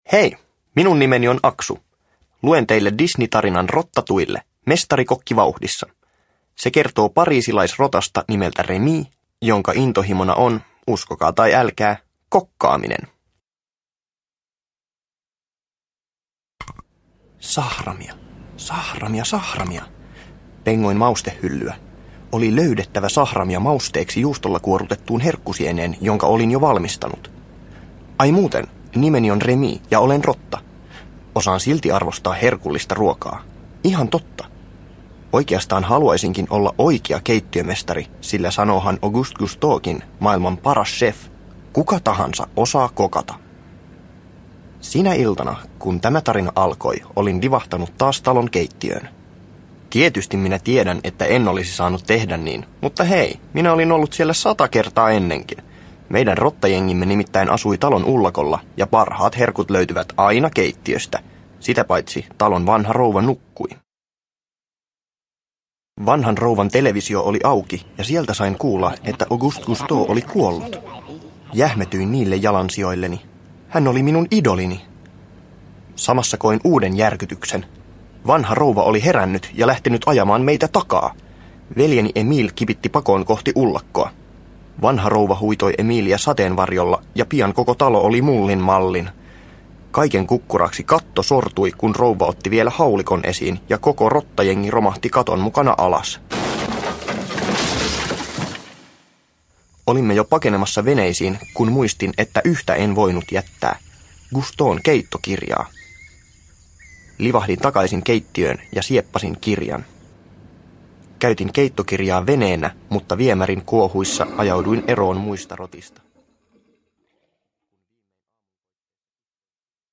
Rottatouille. Mestarikokki vauhdissa – Ljudbok – Laddas ner